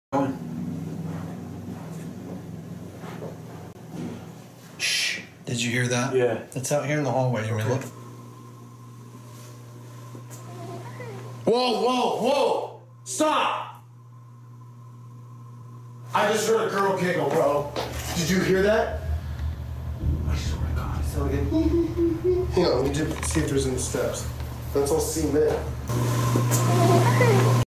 Ghost adventures capture what sounds like a girl giggling...